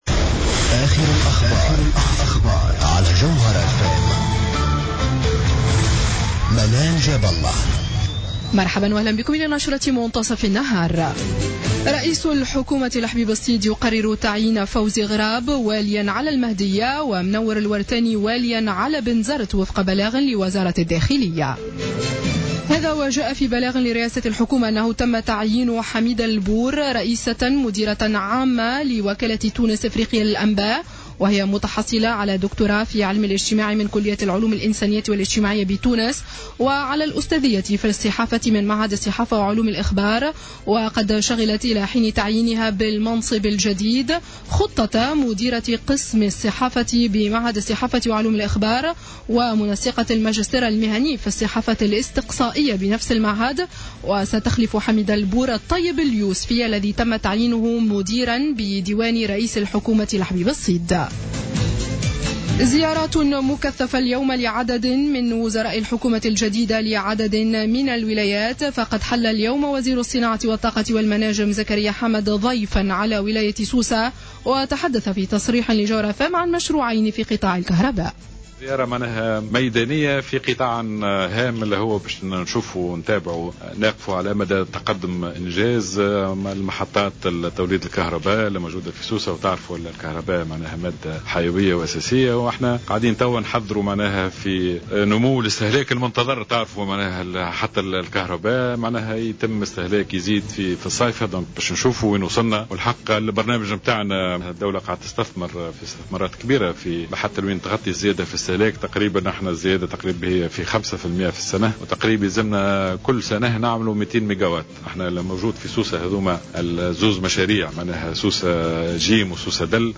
نشرة أخبار منتصف النهار ليوم السبت 14 فيفري 2015